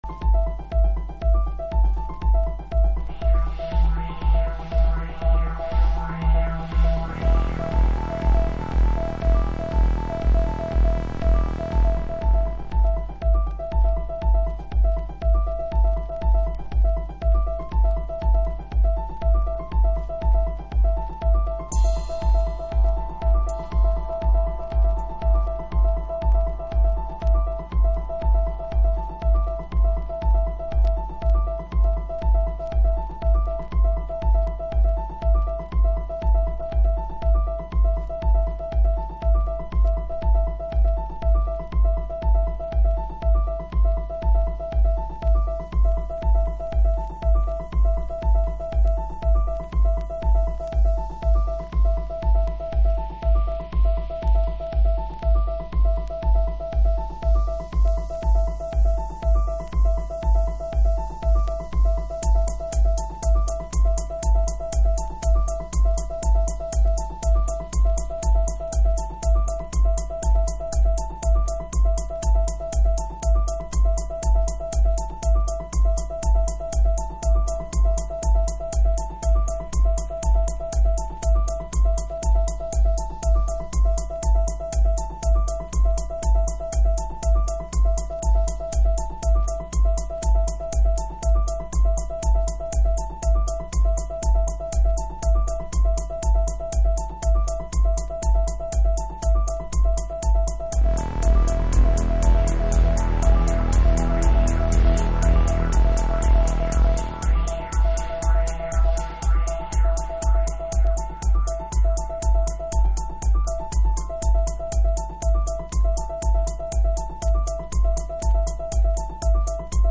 House / Techno
テン年代に入ってもプリセットスタイルは変わらず、音の定位が生々しく感じられ、独自のグルーヴを獲得しています。